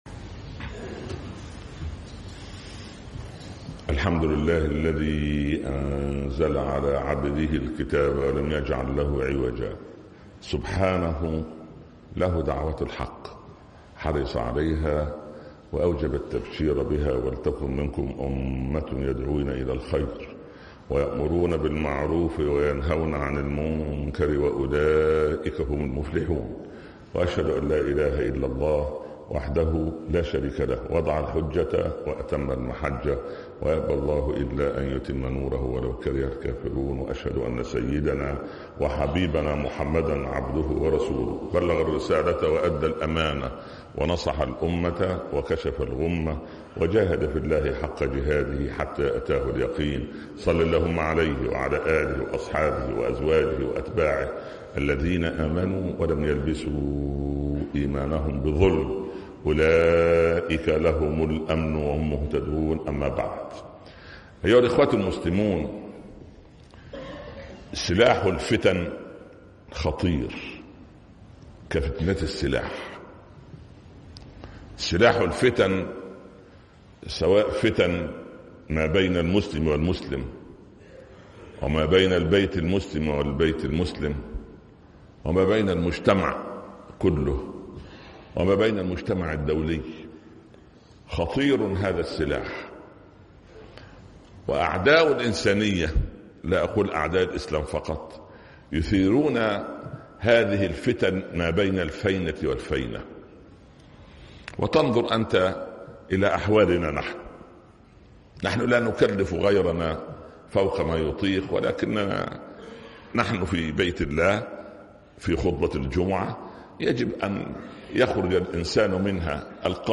سلاح الفتن (خطب الجمعة